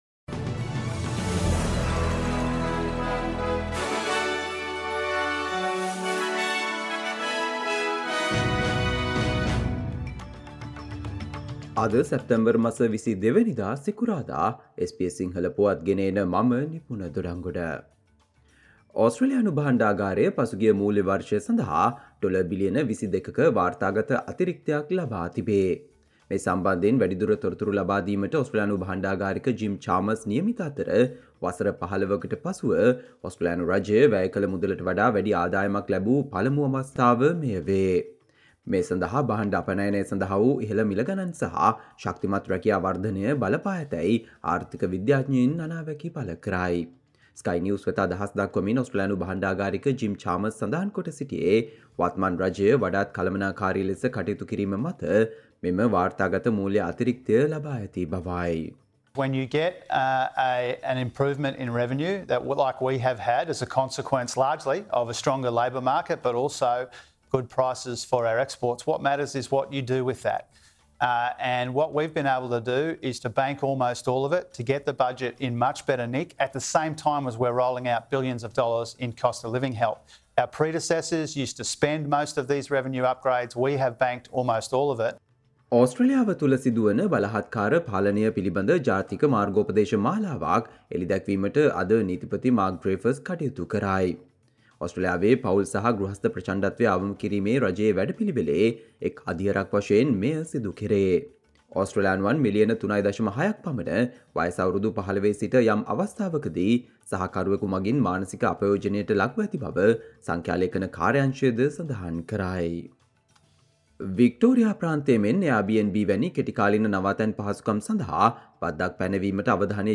Australia news in Sinhala, foreign and sports news in brief - listen, today - Friday 22 September 2023 SBS Radio News